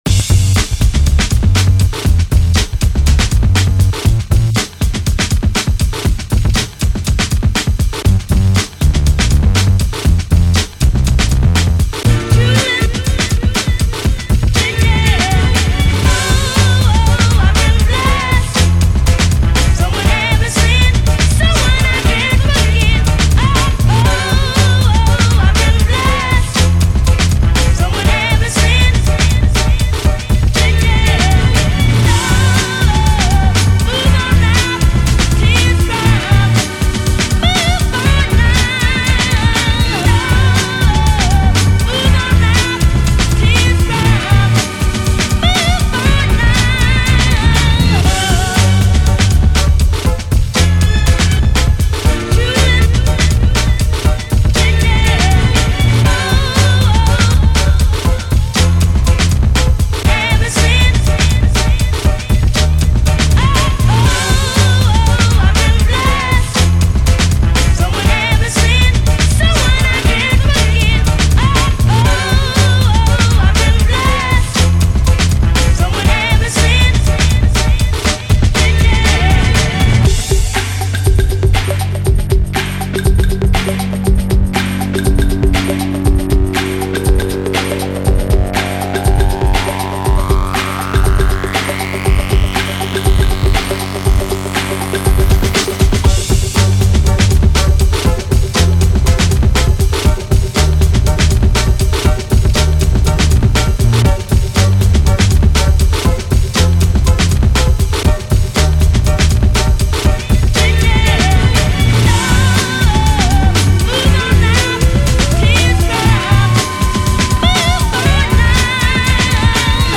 this 5 man production from Portugal